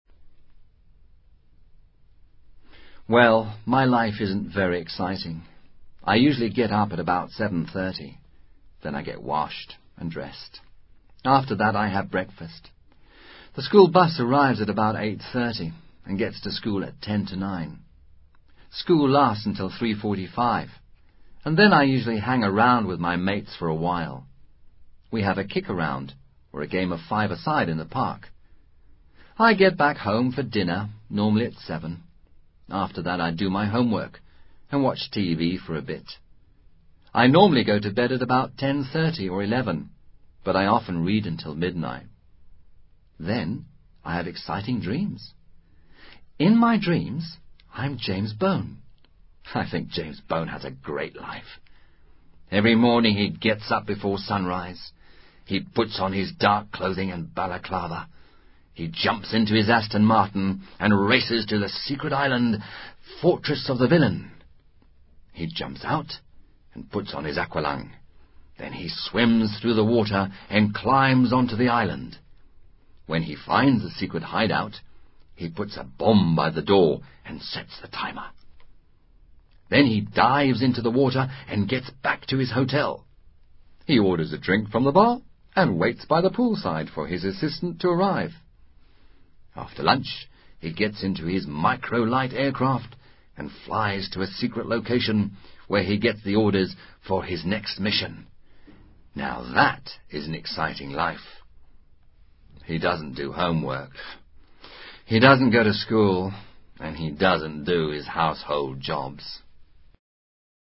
Monólogo fácil de comprender en el que un escolar habla sobre su rutina diaria.